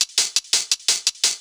UHH_ElectroHatA_170-01.wav